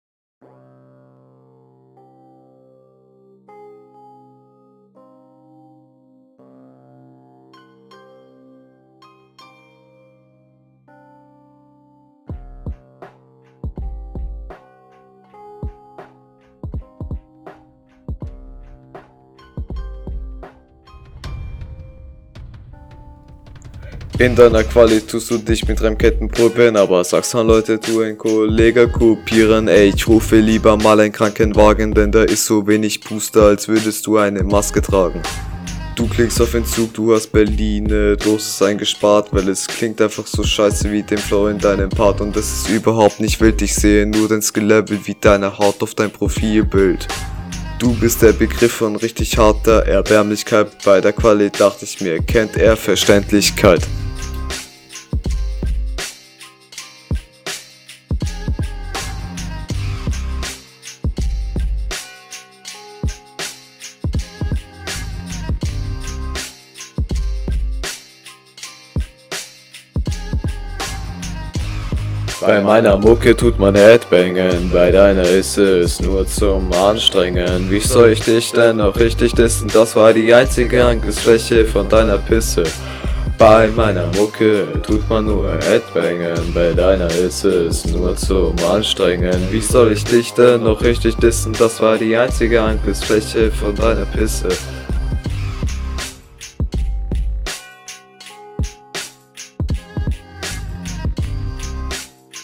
Der Flow klingt sehr eintönig und die Stimme kommt total drucklos rüber.